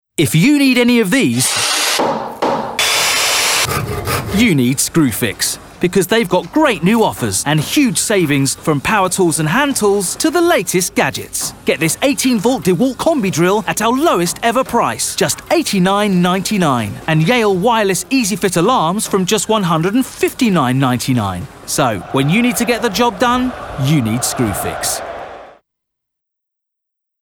• Male
Showing: Commerical Clips
Upbeat, Confident, Clear